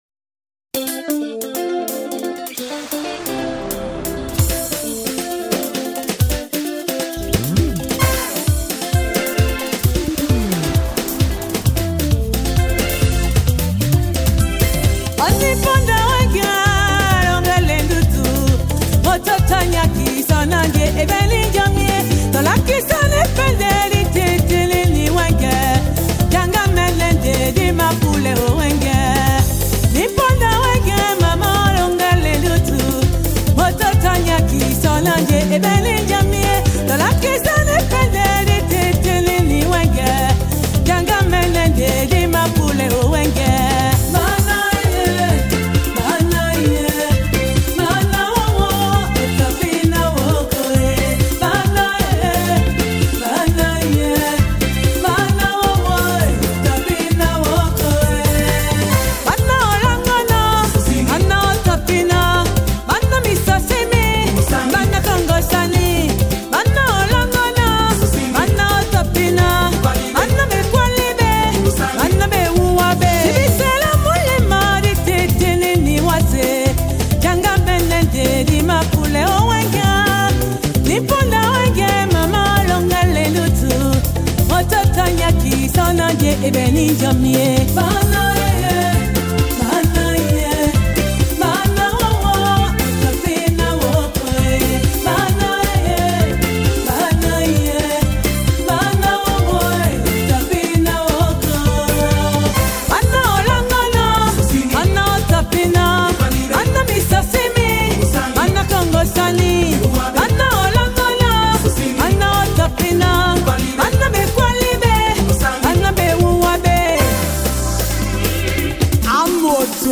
Ma MusiqueSawa